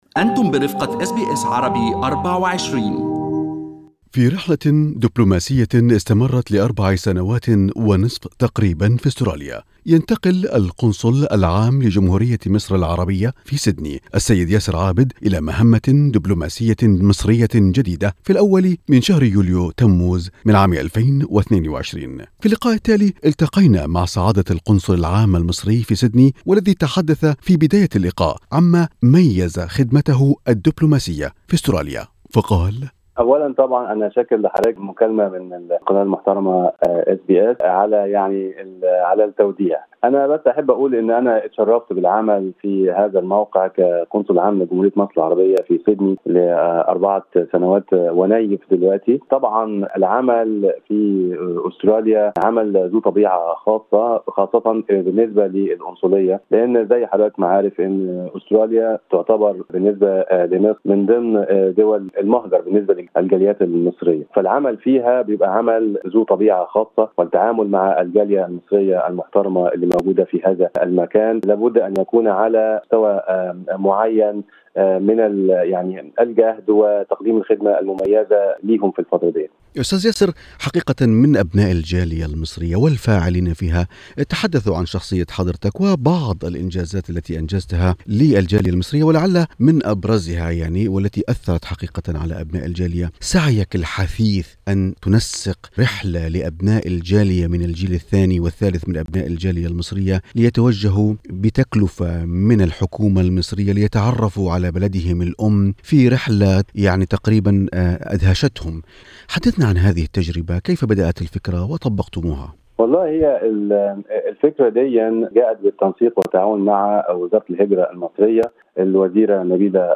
في حديثه مع إذاعة أس بي أس عربي 24 قال سعادة القنصل المصري: " كانت فترة عملي في القنصلية المصرية في سيدني ذات طابع خاص بما تحمله من عمل حثيث لخدمة الجالية المصرية المنتشرة في أستراليا خاصة في ظل الأوقات الصعبة التي مر بها العالم بسبب جائحة كورونا".